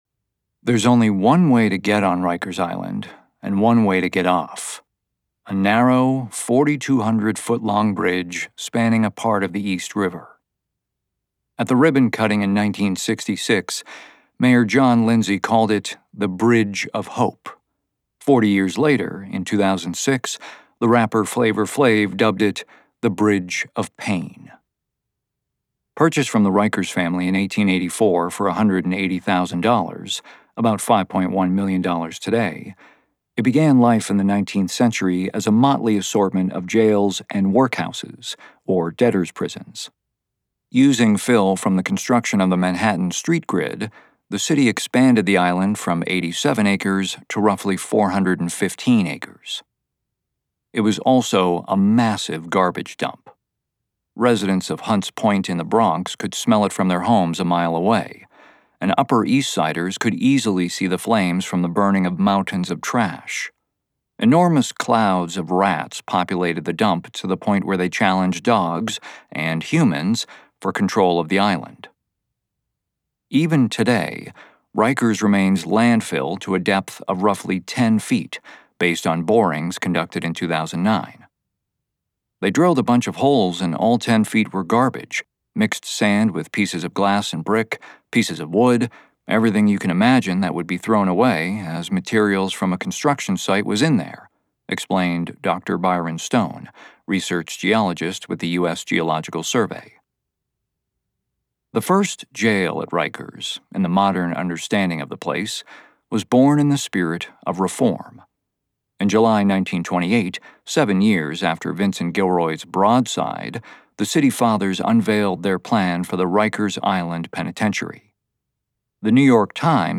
Audiobook Narration, Marketing, and Consulting
Crisp. Wry. Confident.
A voice that cuts right to the chase!